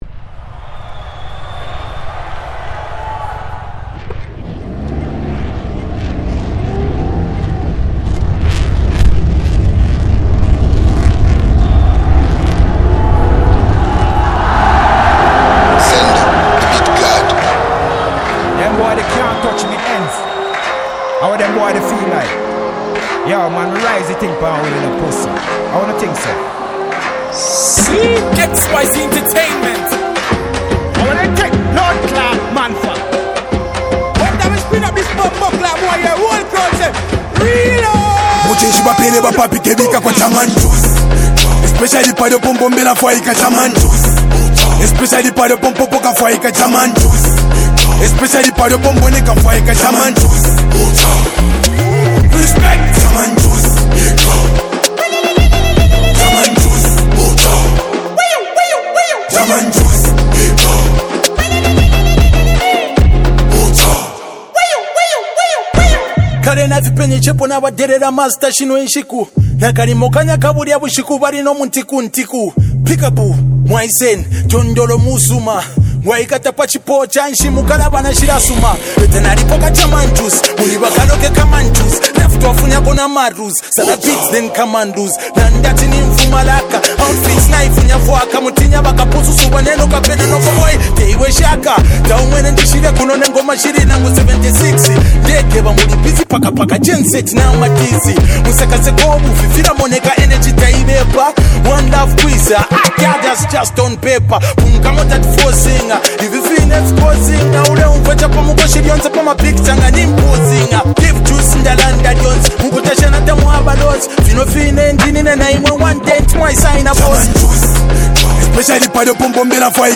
combines elements of hip-hop and Afrobeat
percussion